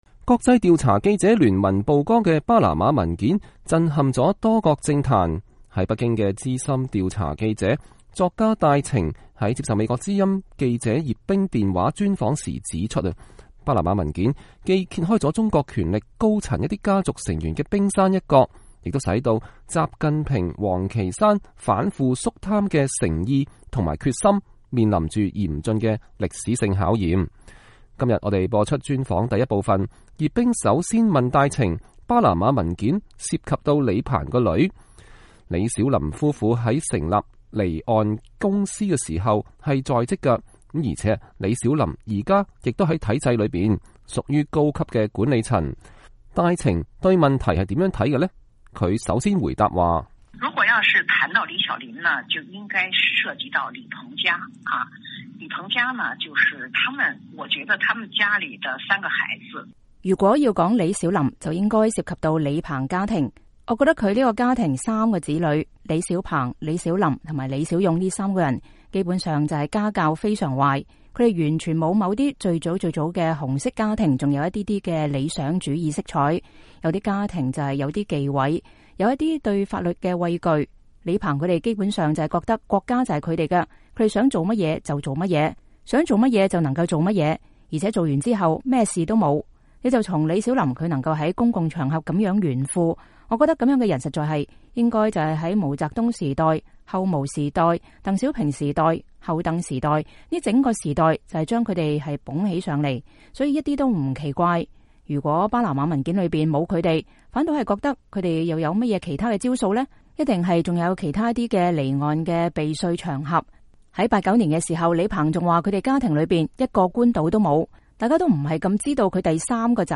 在北京的資深調查記者、作家戴晴在接受美國之音記者電話專訪時指出，巴拿馬文件的披露是近十年來發生的這一最大事件，既揭開了中國權力高層一些家族成員，比如李鵬之女、中國電力一姐李小琳等權貴富豪，涉嫌違法避稅藏富內幕的冰山一角，也使得習近平王岐山反腐肅貪的誠意和決心面臨嚴峻的歷史性考驗。